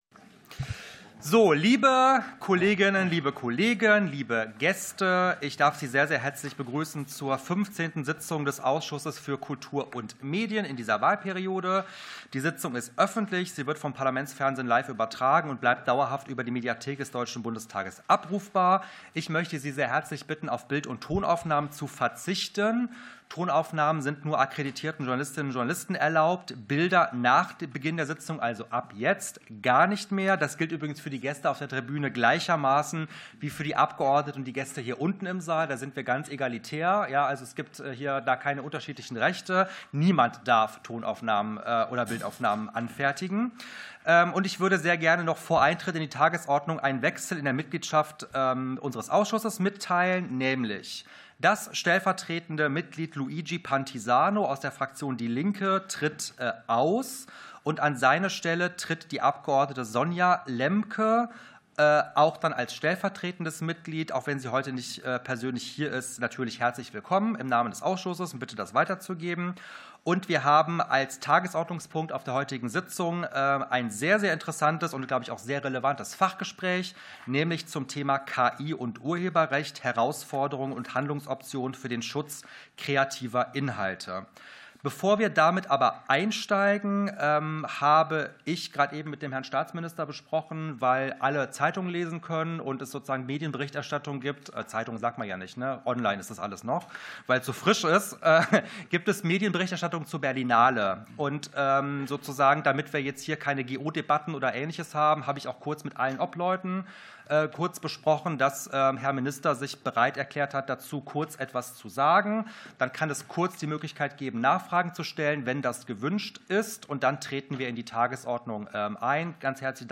15. Sitzung des Ausschusses für Kultur und Medien ~ Ausschusssitzungen - Audio Podcasts Podcast